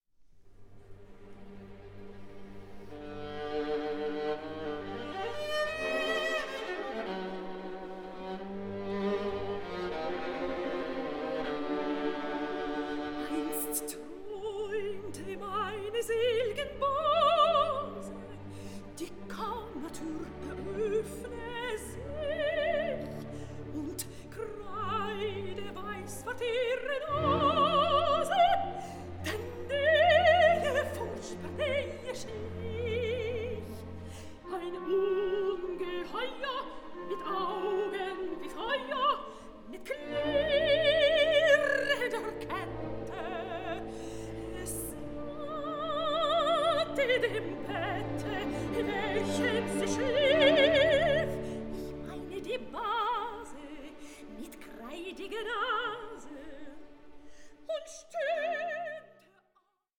THE GERMAN ROMANTIC OPERA PAR EXCELLENCE